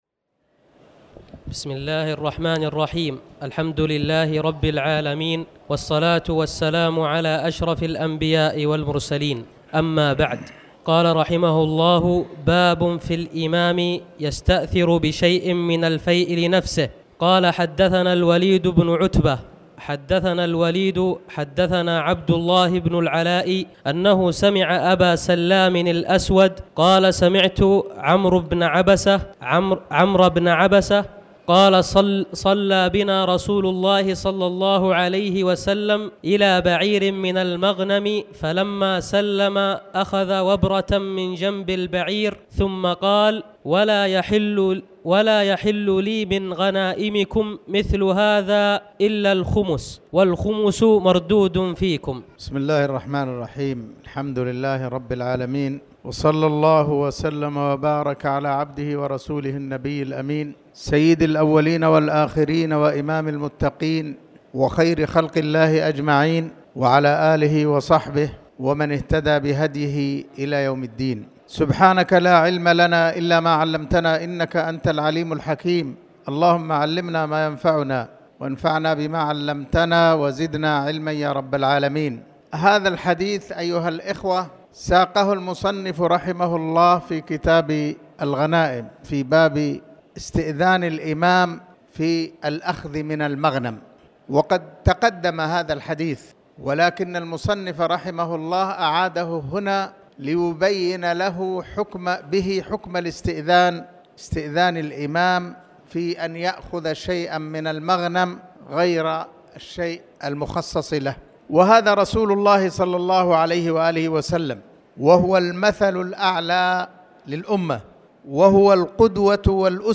تاريخ النشر ١٩ ربيع الثاني ١٤٣٩ هـ المكان: المسجد الحرام الشيخ